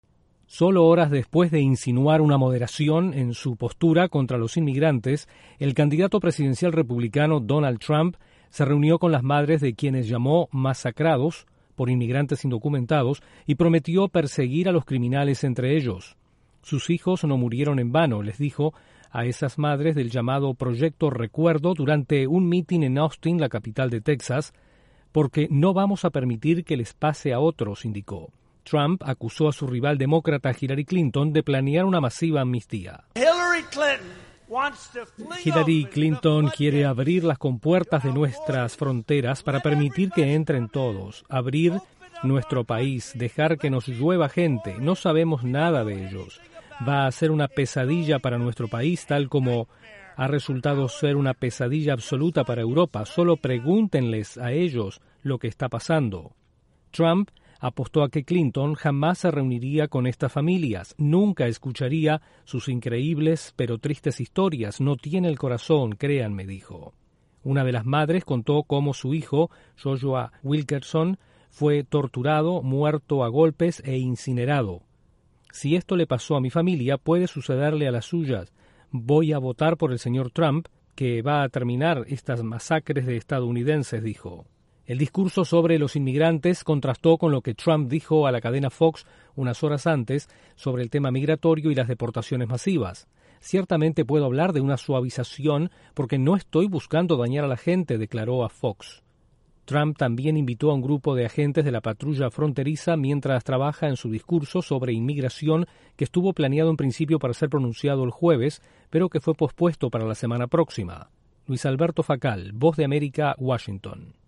Donald Trump habla con madres de muertos a manos de inmigrantes indocumentados durante un acto en Texas.